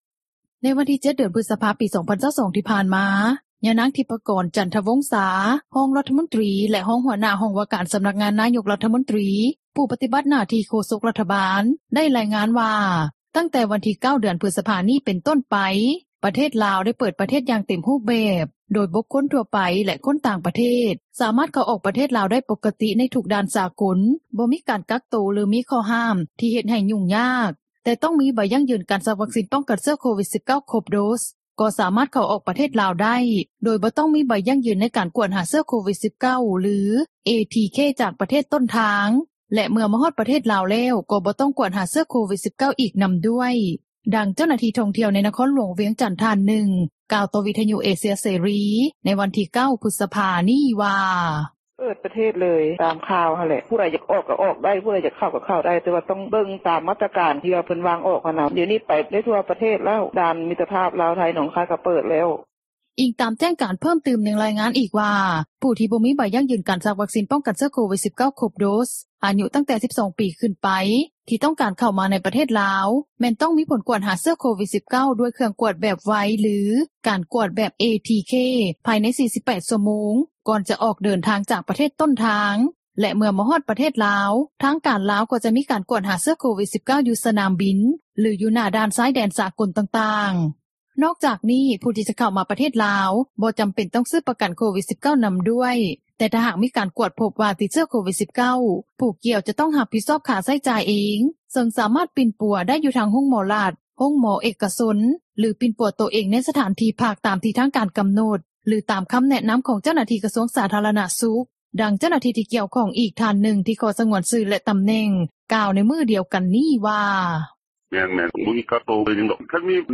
ດັ່ງເຈົ້າໜ້າທີ່ທ່ອງທ່ຽວ ໃນນະຄອນຫຼວງວຽງຈັນທ່ານນຶ່ງ ກ່າວຕໍ່ວິທຍຸເອເຊັຽເສຣີ ໃນວັນທີ່ 9 ພຶສພາ ນີ້ວ່າ: